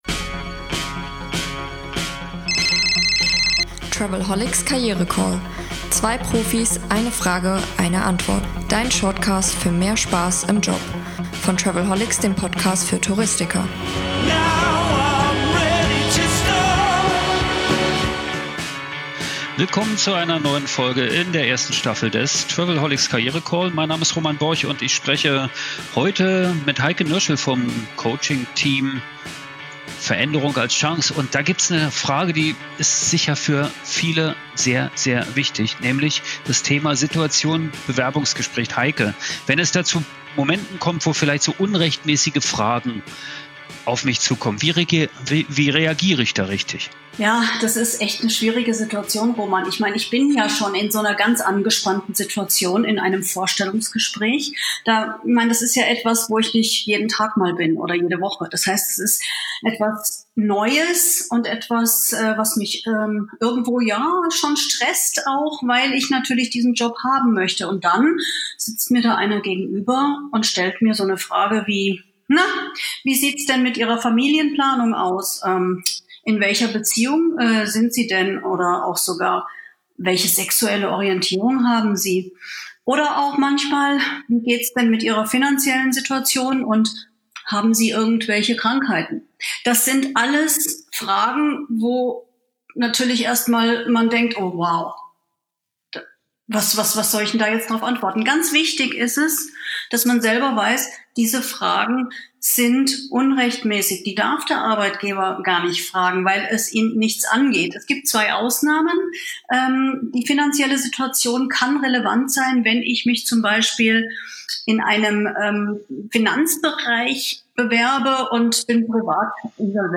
Der travelholics KarriereCALL Staffel 1: täglich - 2 Profis - 1 Frage - 1 Mic
In den kurzen Bonus Episoden des Podcast für Touristiker spreche ich mit den Coaches vom Team Veränderung als Chance über berufliche Neuorientierung und ganz praktische Tipps für mehr Freude und Erfolg im Job.